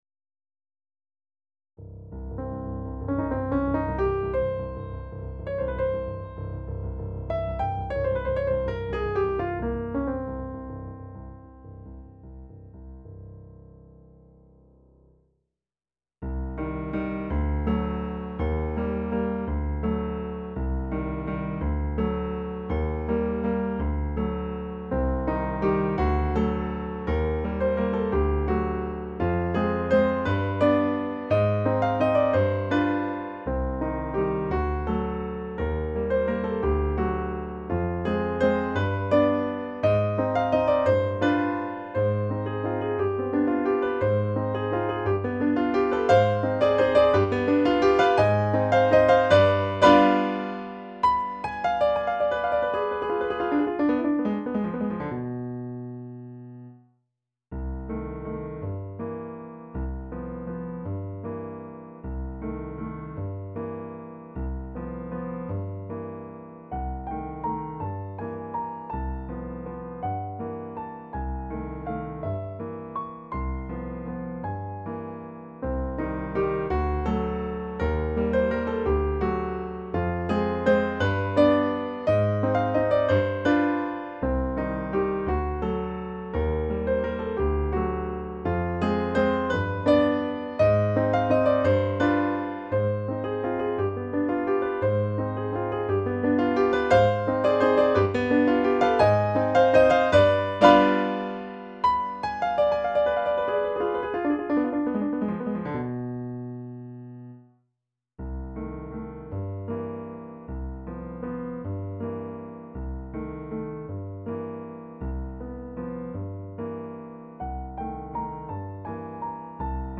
ゲーム、漫画のイラストを書いたり、ゲームのピアノ楽譜を作ったり、ゲームの動画を投稿しているサイト。